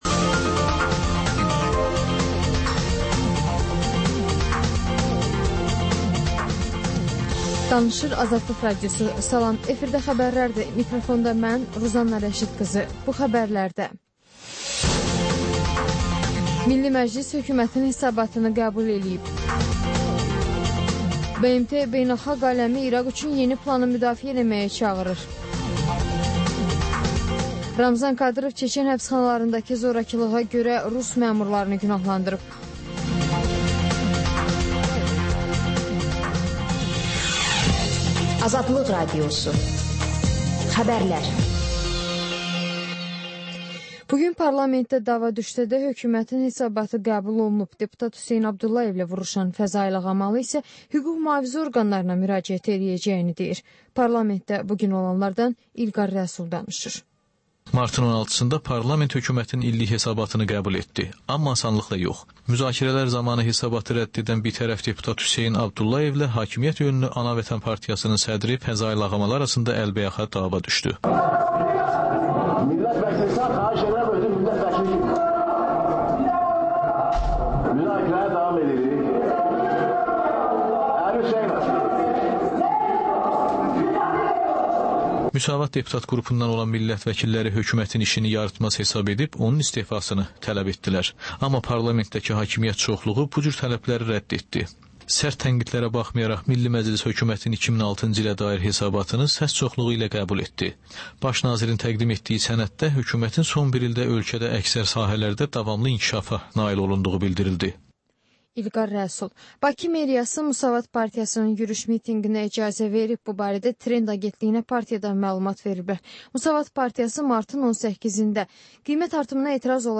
Xəbərlər, reportajlar, müsahibələr. Və: Günün Söhbəti: Aktual mövzu barədə canlı dəyirmi masa söhbəti.